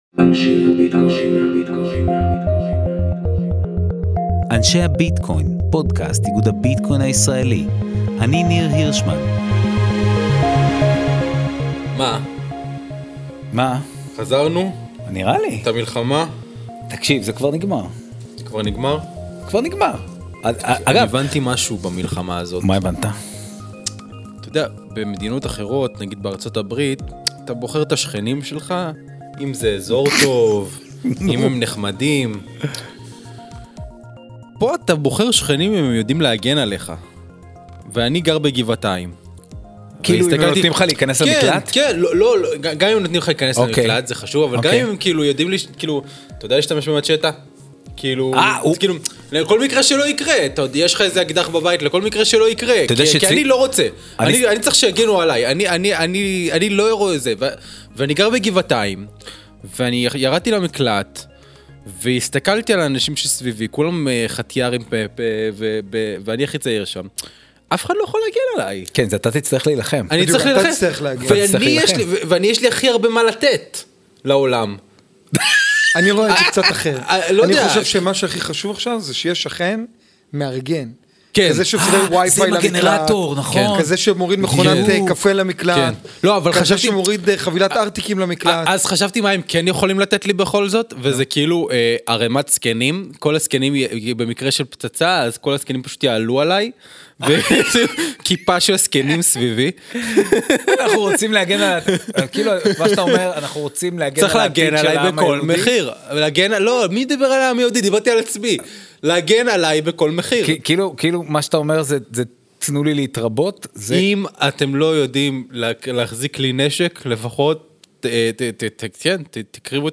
לשיחה על חוק ה-GENIUS – החוק הפדרלי החדש שעבר בארה"ב ומסדיר את תחום המטבעות היציבים.